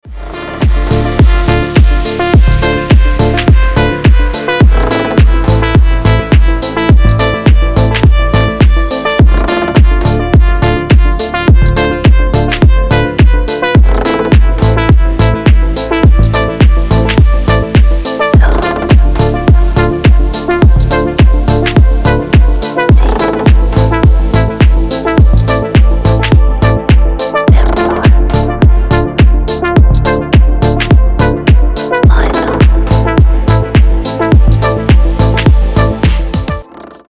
• Качество: 320, Stereo
ритмичные
deep house
Electronic
Атмосферный дипчик на рингтон